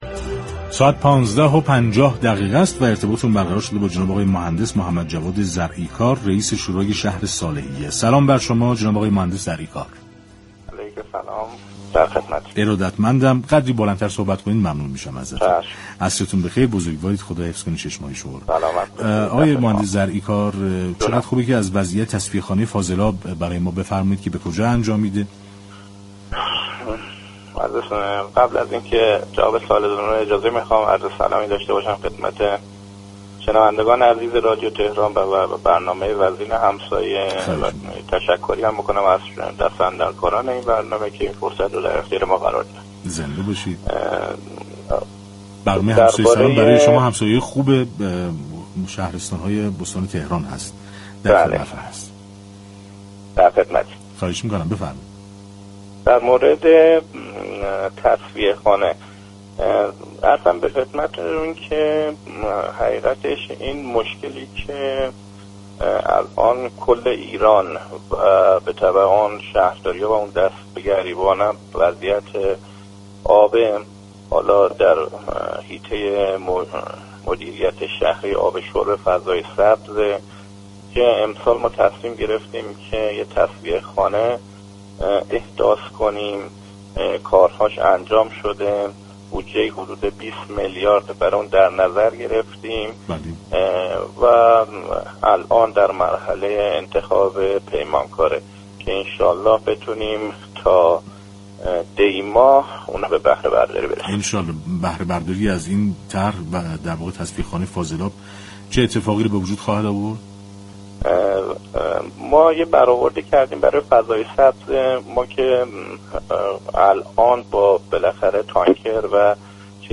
به گزارش پایگاه اطلاع رسانی رادیو تهران، محمدجواد زرعی كار رئیس شورای شهر صالحیه در مصاحبه با برنامه "همسایه سلام" ، تامین آبِ مورد نیازِ فضای سبز این شهرستان را در گرو راه‌اندازی این تصفیه خانه در آینده نزدیك عنوان كرد.